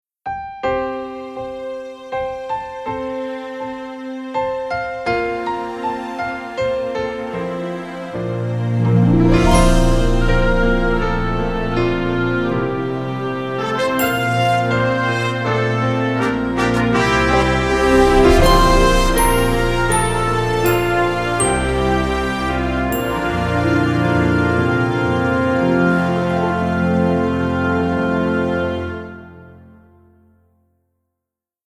inspirational contemporary instrumental compositions